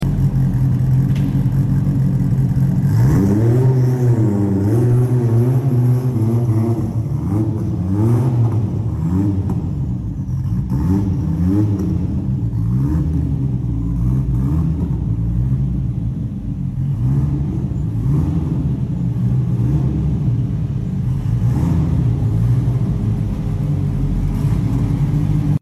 Pure sou d R5 Turbo sound effects free download